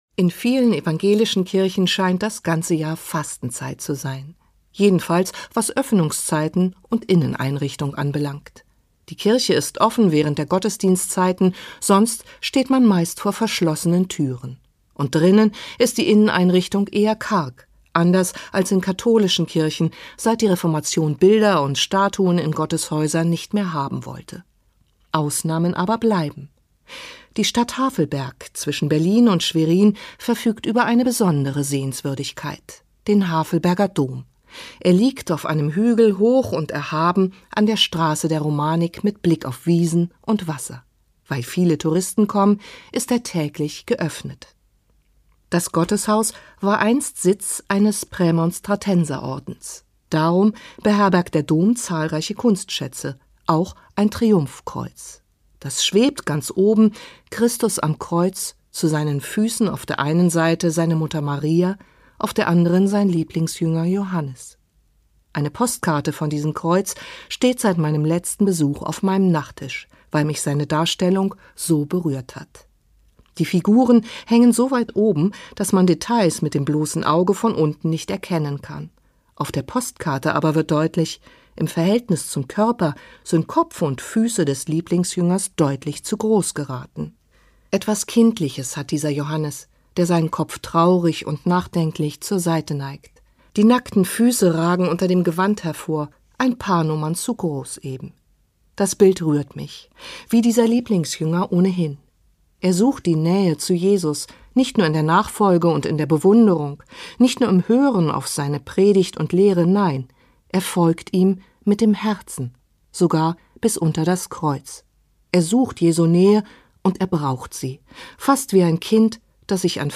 Evangelische Andachten am Morgen